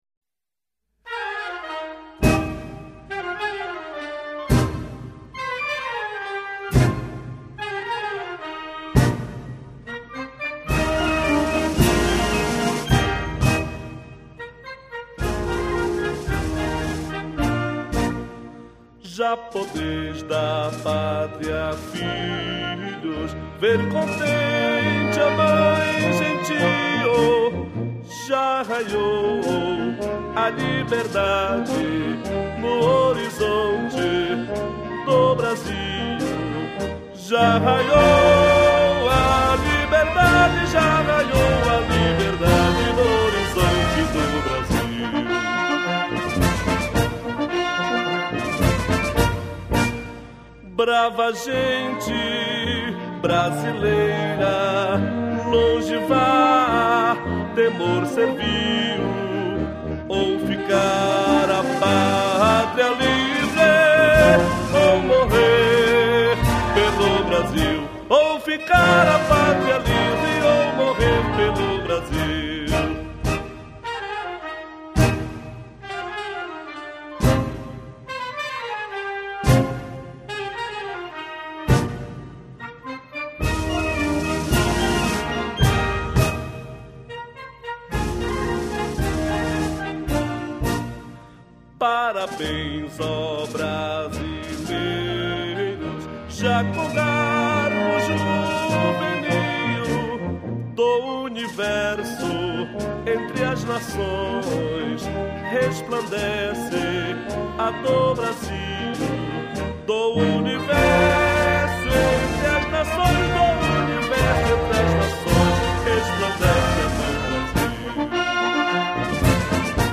HinoIndependenciaCantado.mp3